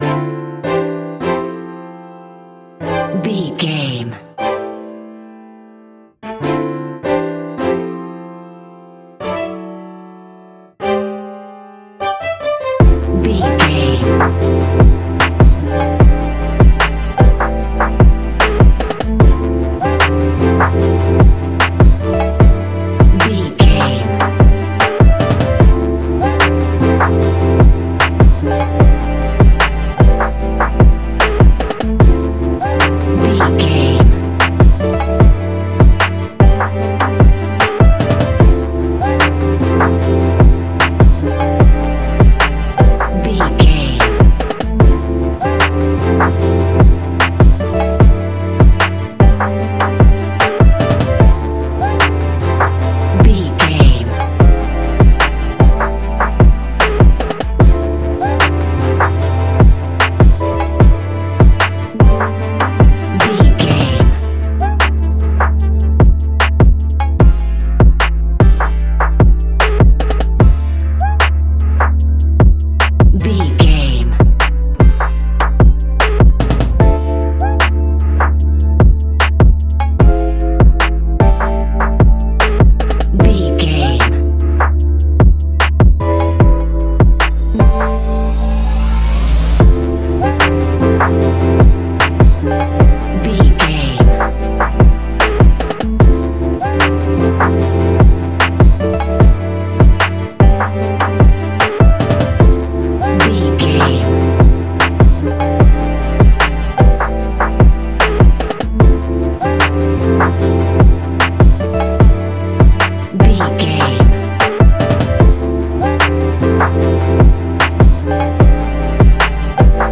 Ionian/Major
D♭
chilled
laid back
Lounge
sparse
new age
chilled electronica
ambient
atmospheric
instrumentals